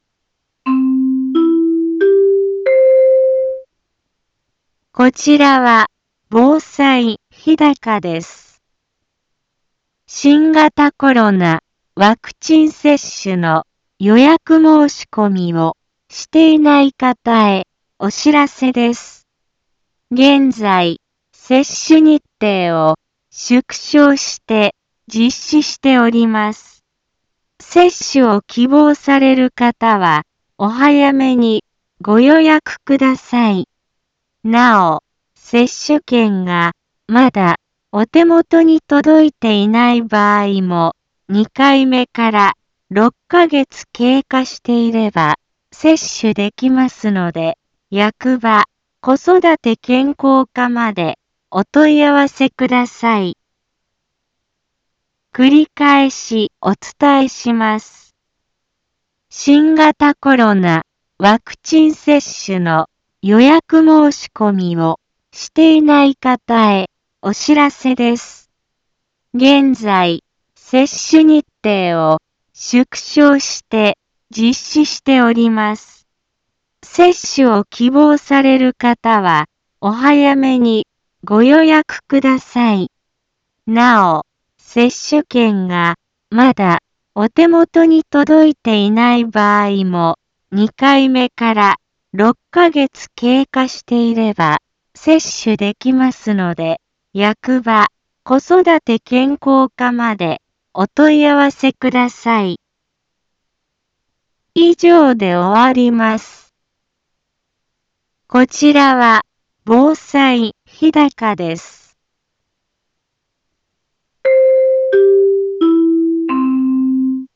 一般放送情報
Back Home 一般放送情報 音声放送 再生 一般放送情報 登録日時：2022-04-18 15:04:06 タイトル：新型コロナワクチン予防接種のお知らせ インフォメーション：こちらは防災日高です。 新型コロナワクチン接種の予約申込みをしていない方へお知らせです。